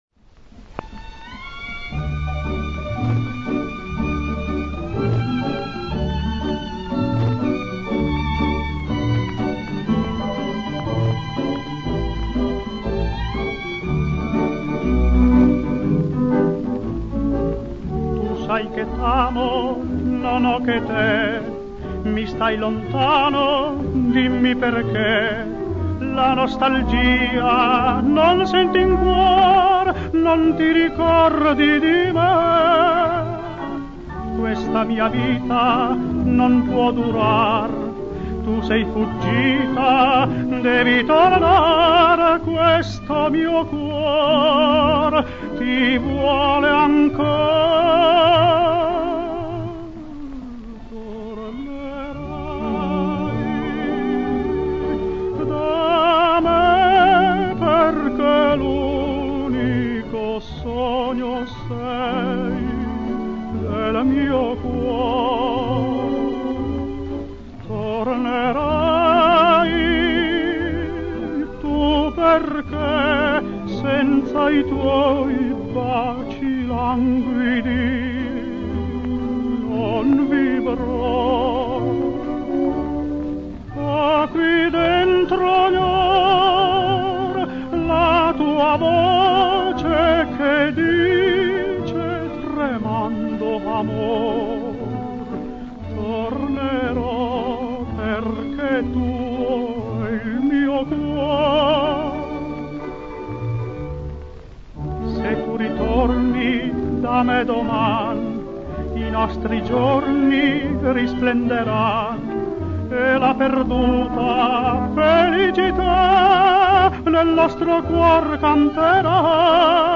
Da supporto 78 giri
Dalla voce del tenore